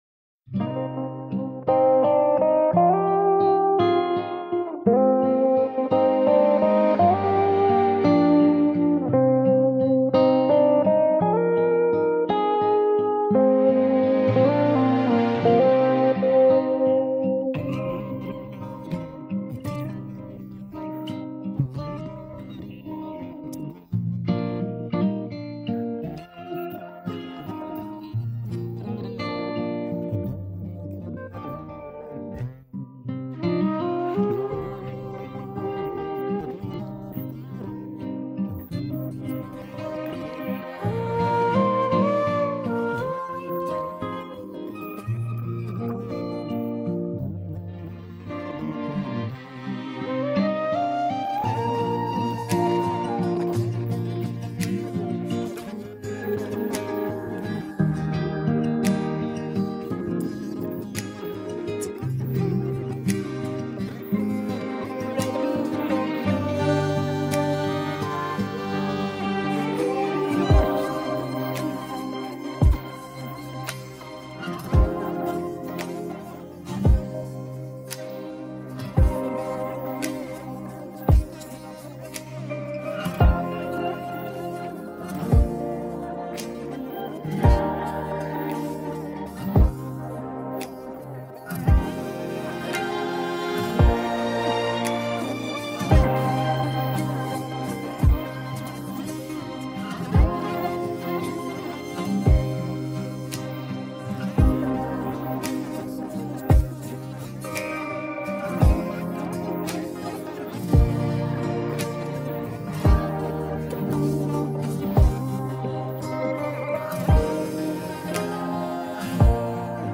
(איכות גבוהה)
הפלייבק הזה נעשה בתוכנה חינמית…